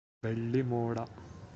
pronunciation transl.